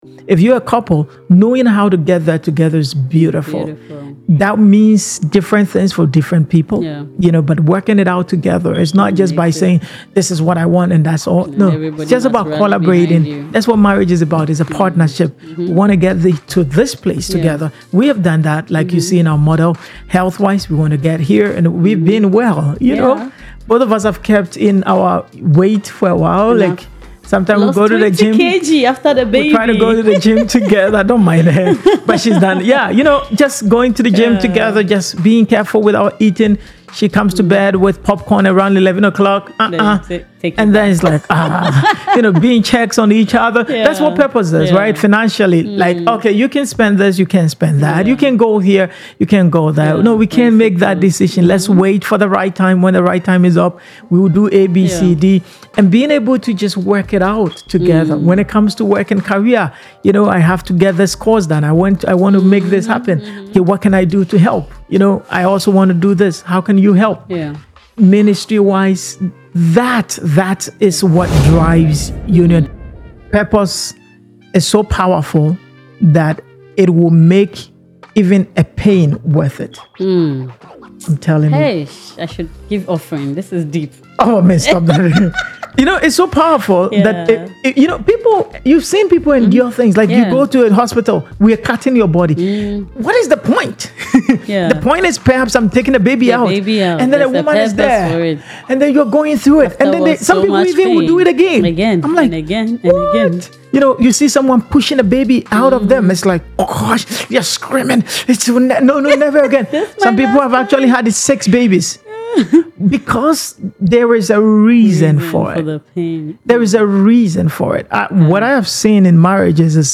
Learn how to stop fighting against each other and start building a beautiful life together. Whether you're dating, engaged, or have been married for years, this conversation will give you the practical wisdom you need to thrive.